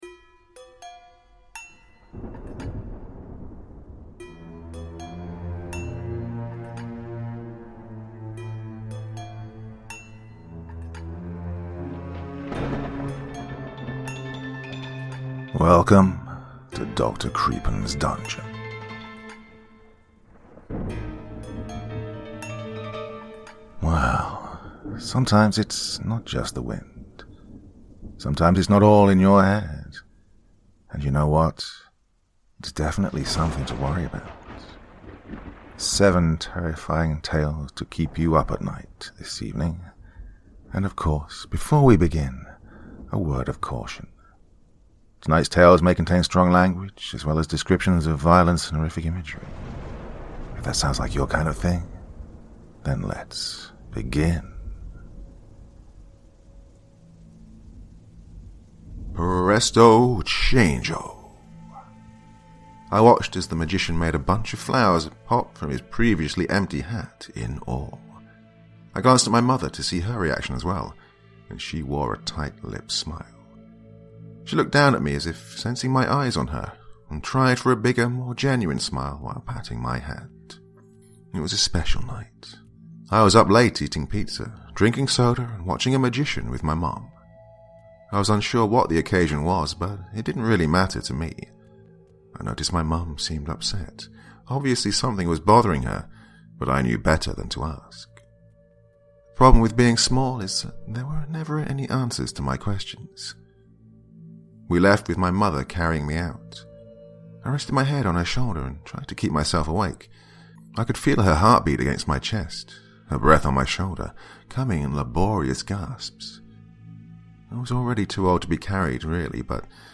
‘The Walk’ is our first tale of terror; an original story by Coffee Glitter Queen, kindly shared directly with me for the express purpose of having me exclusively narrate it here for you all.